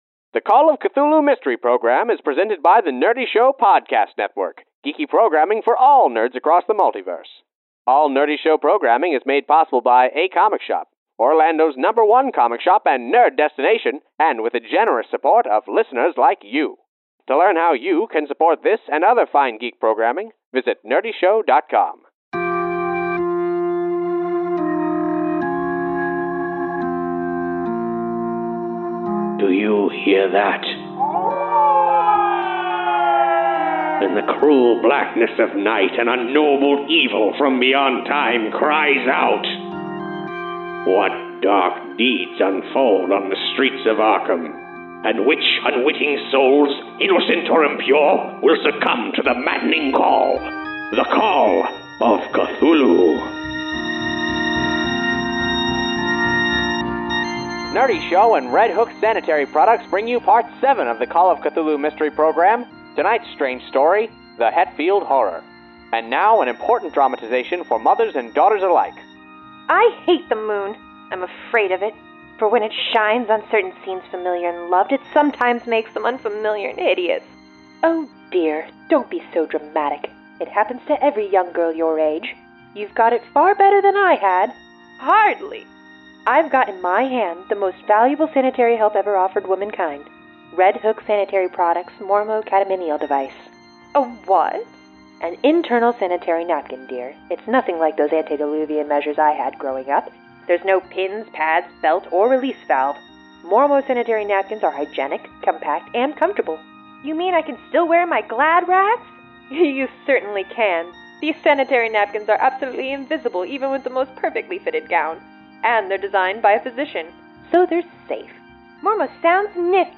The Call of Cthulhu Mystery Program is live tabletop roleplaying turned into a 1930s radio serial.